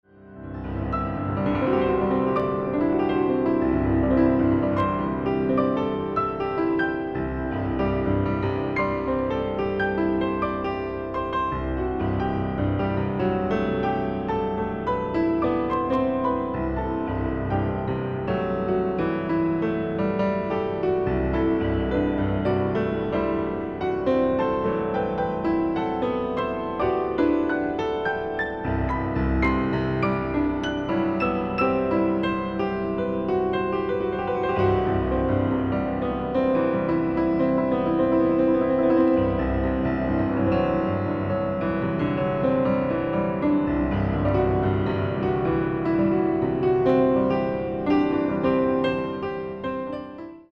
ライブ・アット・ニューヨーク・シティ、ニューヨーク